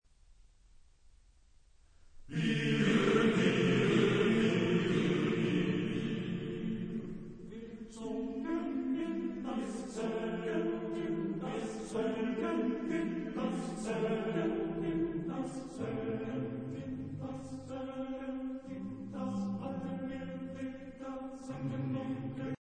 Genre-Style-Forme : Madrigal ; Profane
Tonalité : la mineur